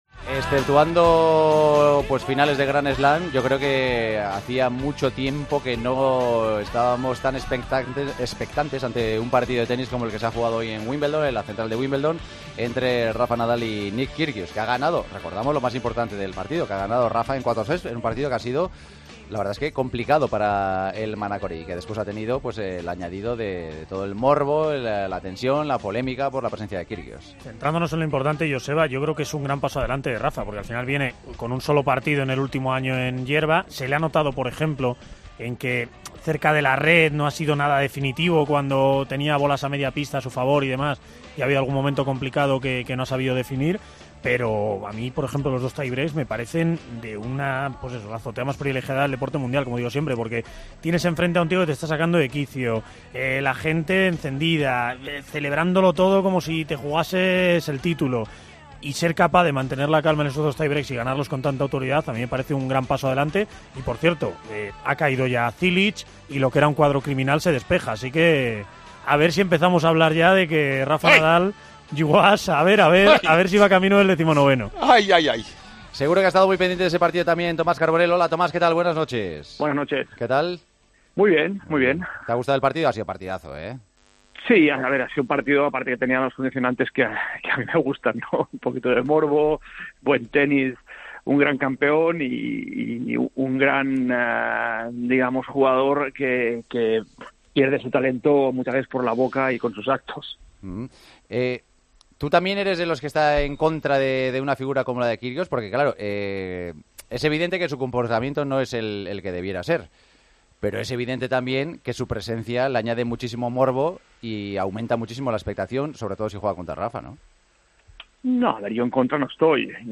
Las excentricidades del tenista australiano durante el partido ante Rafa Nadal en la segunda ronda de Wimbledon, a debate, en El Partidazo de COPE.